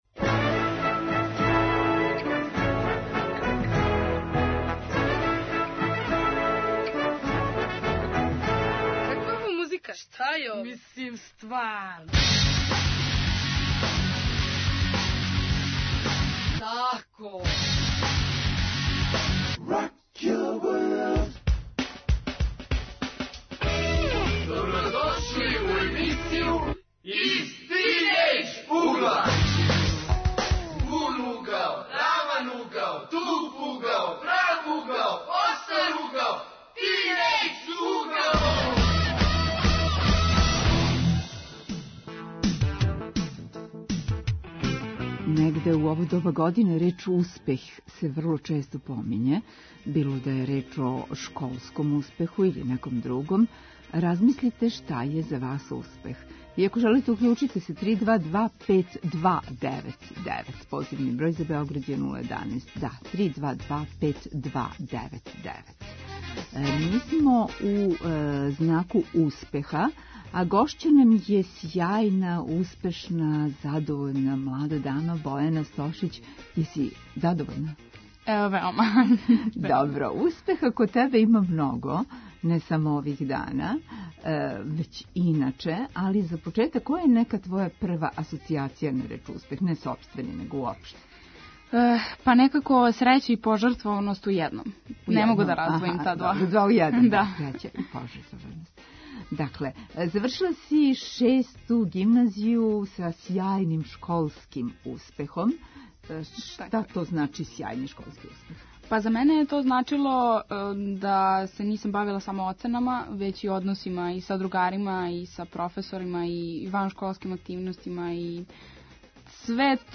Гости: тинејџери.